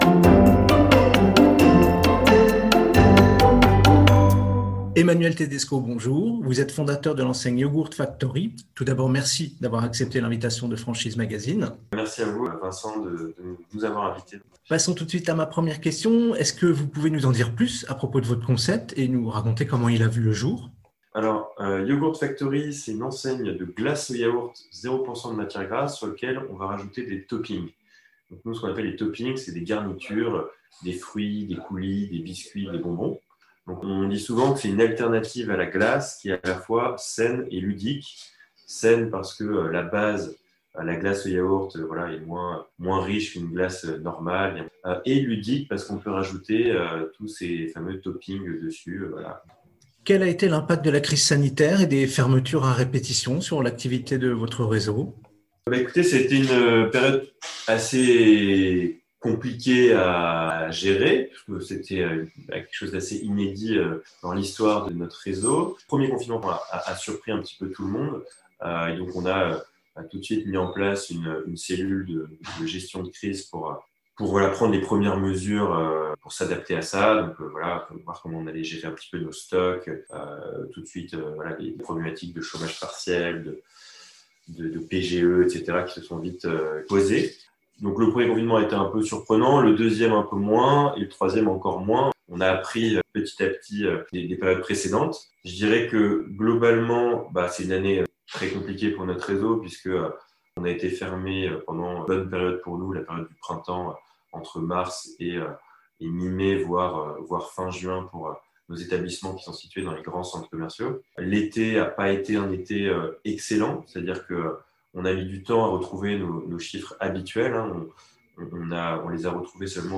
Au micro du podcast Franchise Magazine : la Franchise Yogurt Factory - Écoutez l'interview